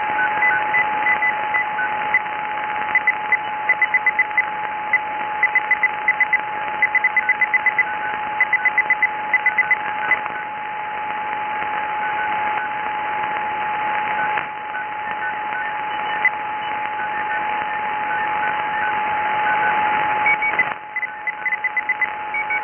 Sound_160m_CW